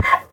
Minecraft Version Minecraft Version latest Latest Release | Latest Snapshot latest / assets / minecraft / sounds / mob / horse / donkey / hit2.ogg Compare With Compare With Latest Release | Latest Snapshot